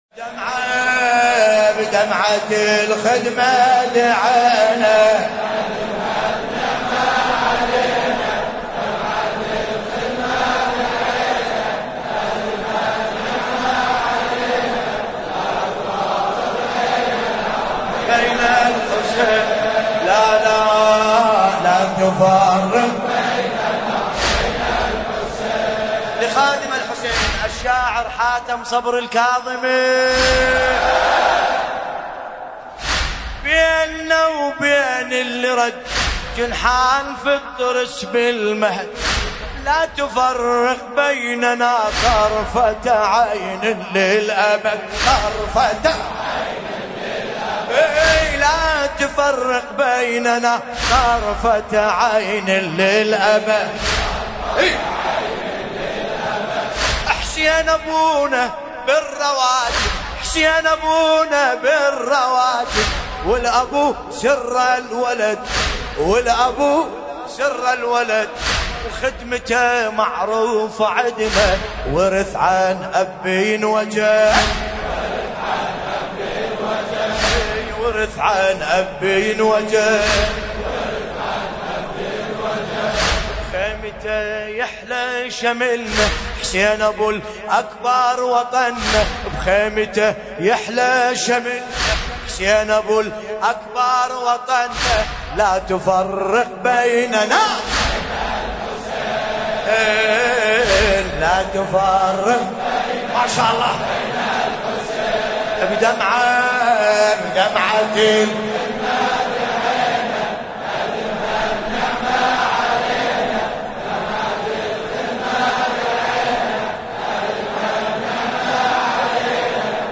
لطمية
الرادود باسم الكربلائي